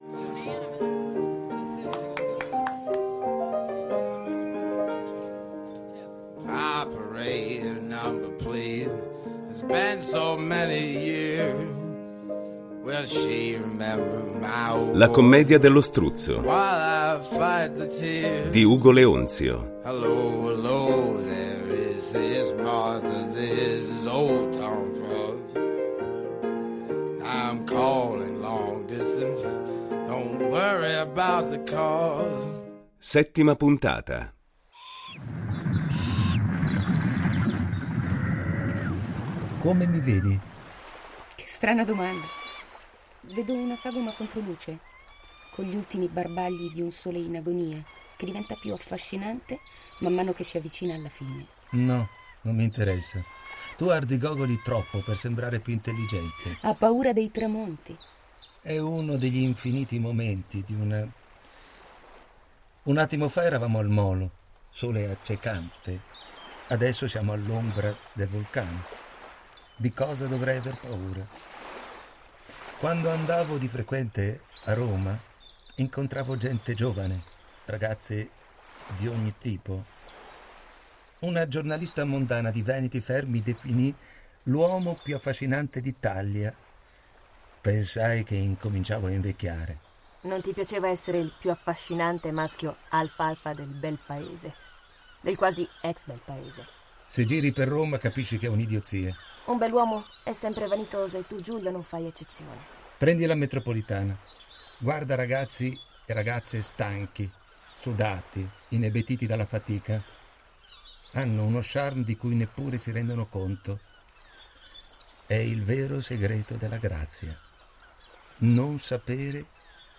sceneggiato